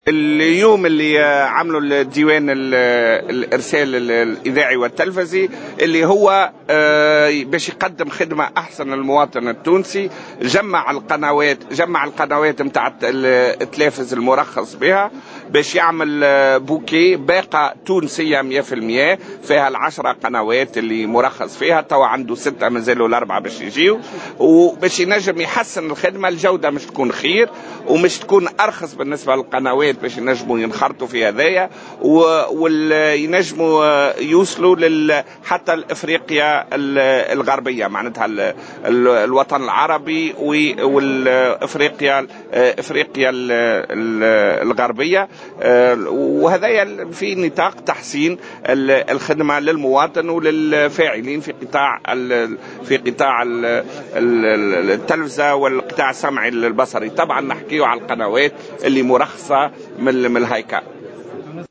وقال وزير تكنولوجيا الاتصال والاقتصاد الرقمي، نعمان الفهري في تصريح إعلامي إن الباقة الرقمية ستضم في مرحلة أولى 6 قنوات خاصة مرخص لها والقناتين الوطنيتين 1 و 2 لتشمل فيما بعد 10 قنوات.